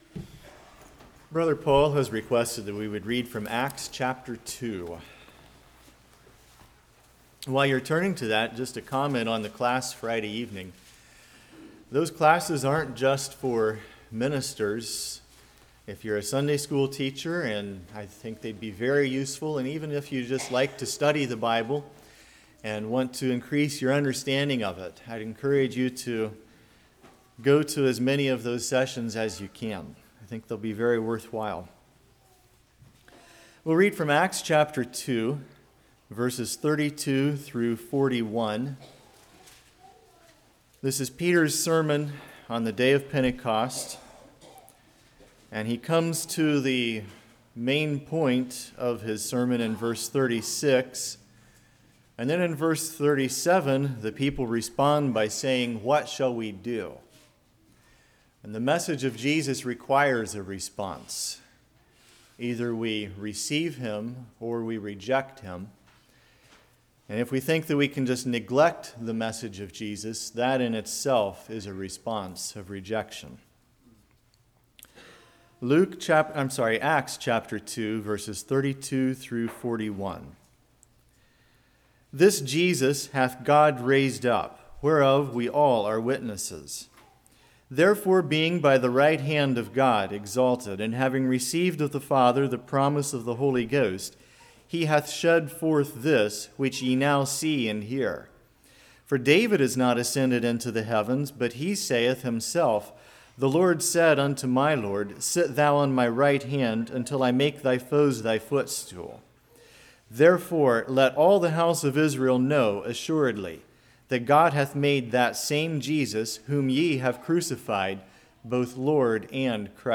Acts 2:32-41 Service Type: Morning Do All Believers Receive?